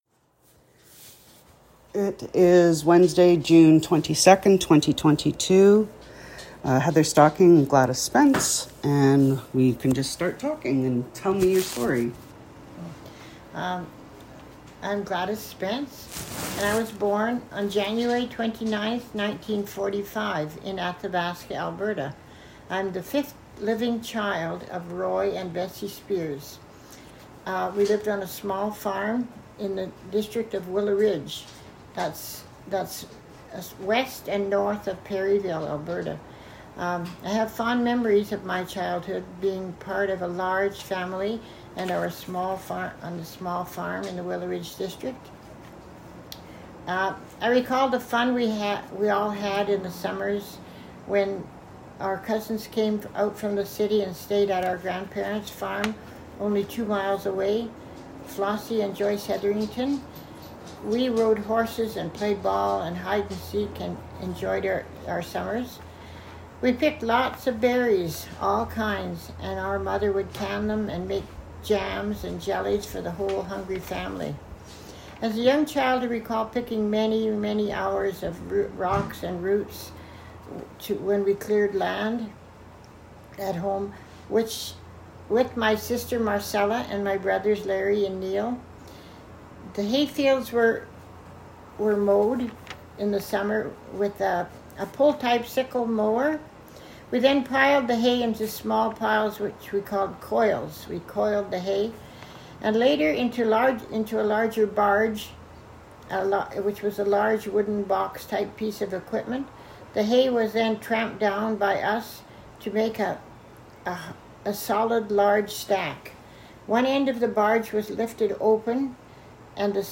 Audio interview and transcript of audio interview,